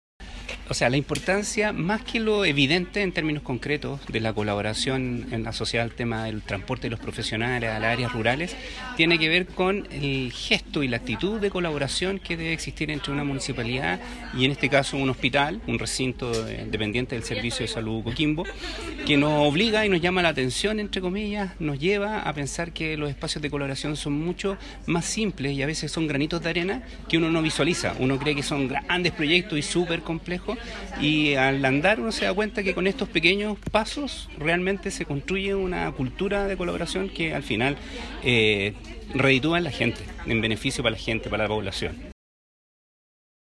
Fue en una significa ceremonia en la que se concretó este acuerdo, instancia en la que participó el director (S) del Servicio de Salud Coquimbo, Dr. Gabriel Sanhueza, quien señaló que